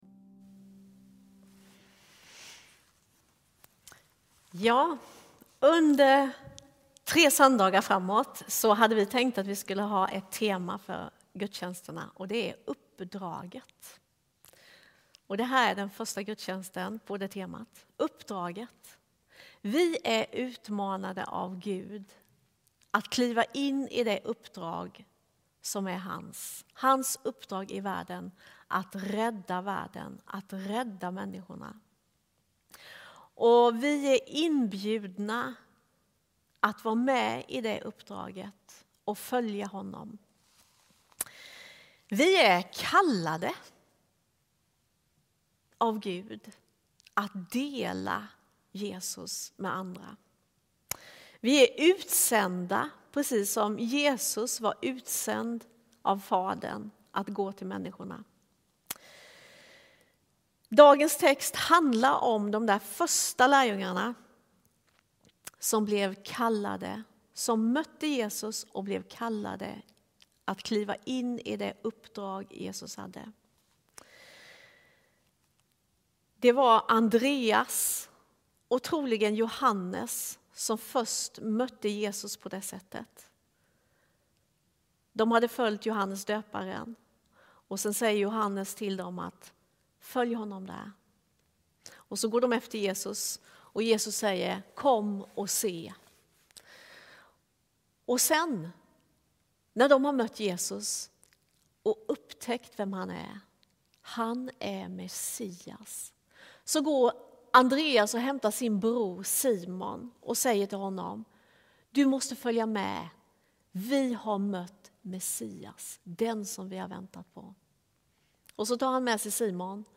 Samtal